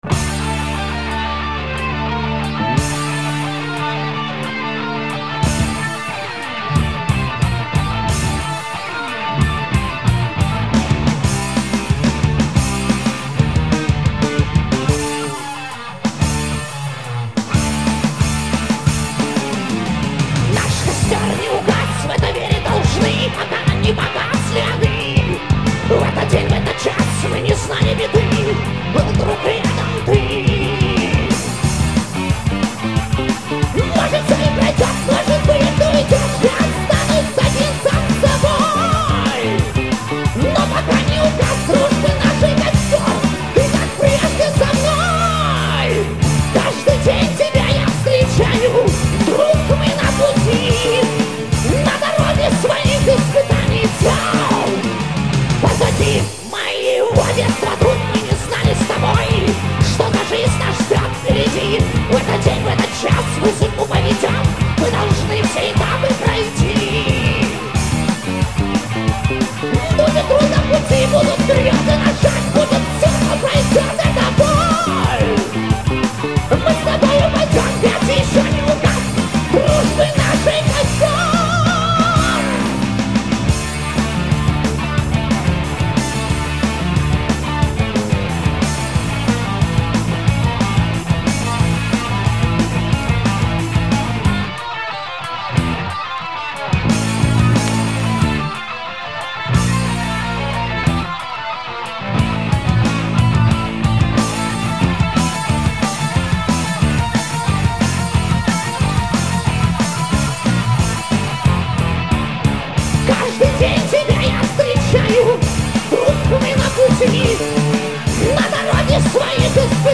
вокал
гитара
бас
барабаны
Запись студии "Диалог" (г. Николаев), 1991